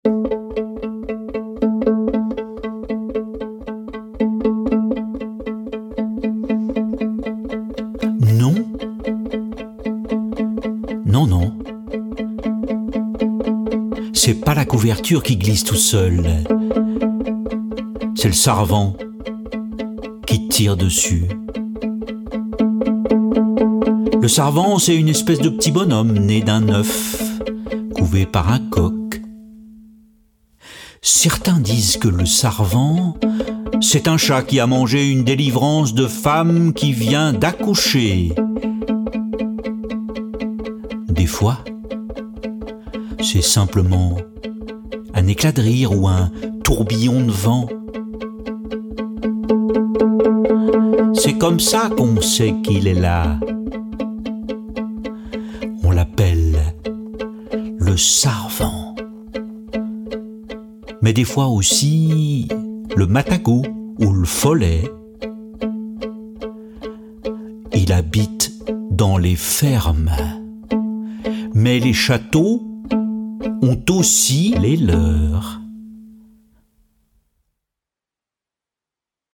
les raconte en s’accompagnant du violon.